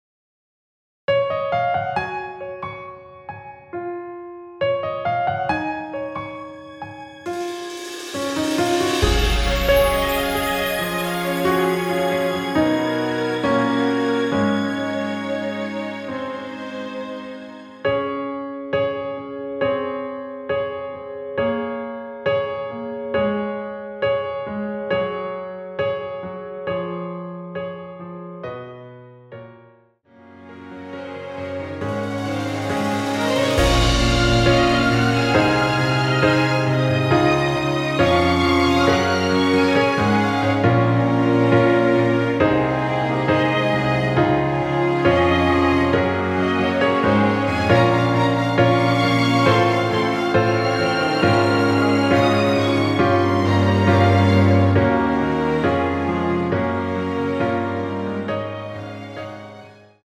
남성분이 부르실 수 있는 키의 MR입니다.(미리듣기 참조)
원키에서(-8)내린 MR입니다.
Db
앞부분30초, 뒷부분30초씩 편집해서 올려 드리고 있습니다.
중간에 음이 끈어지고 다시 나오는 이유는